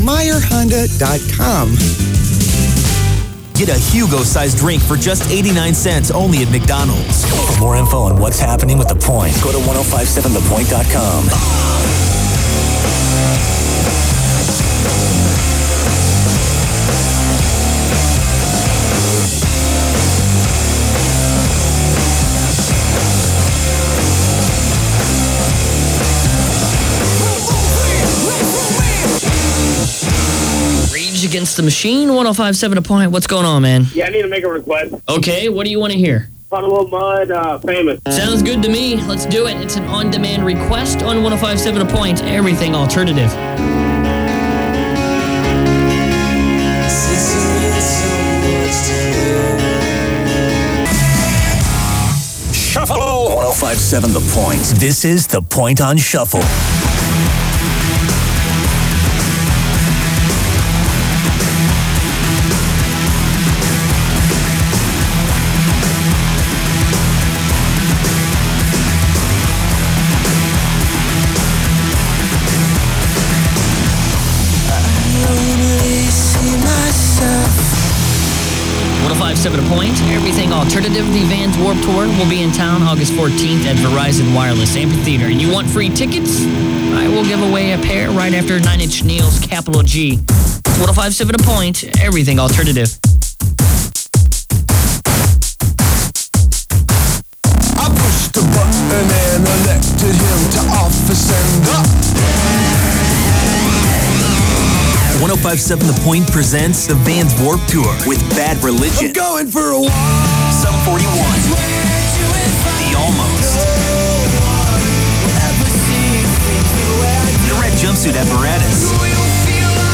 KPNT Unknown Aircheck · St. Louis Media History Archive